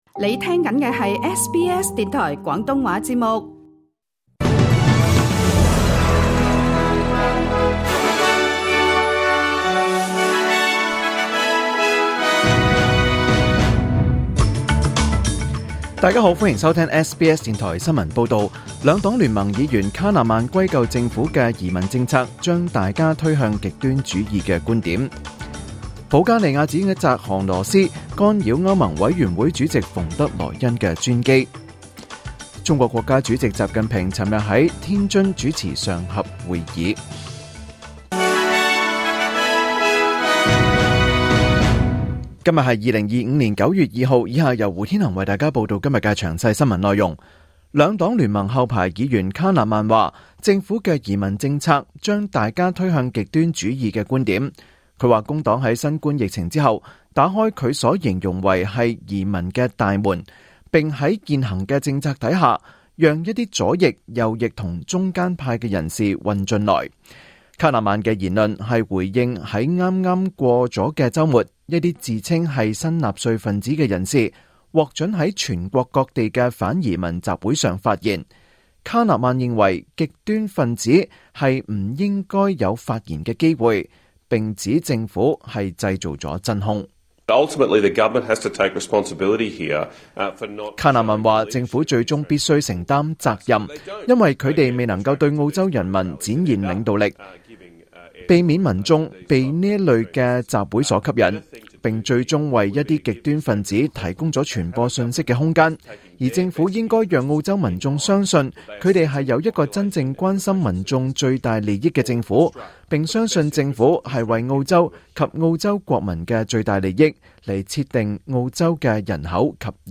2025 年 9 月 2 日 SBS 廣東話節目詳盡早晨新聞報道。